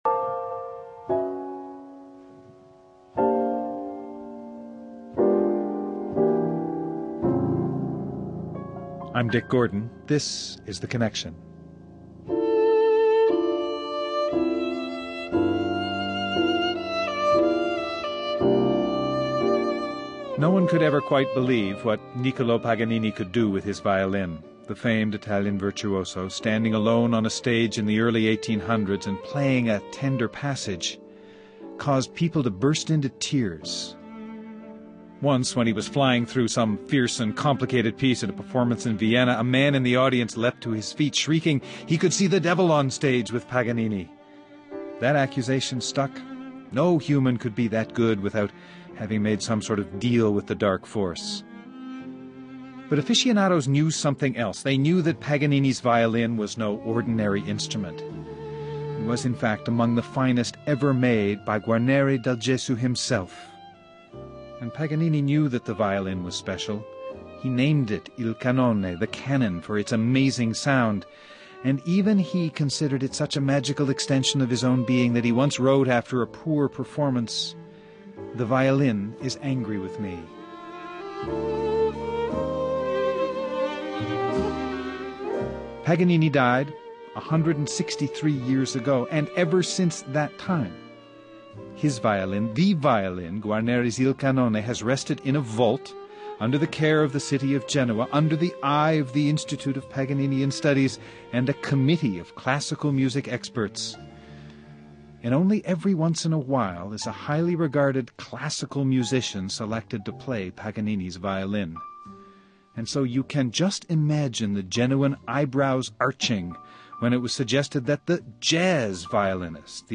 Regina Carter, fiddling with the conventions of jazz, and playing a remarkable instrument.
Guests: Regina Carter, violinist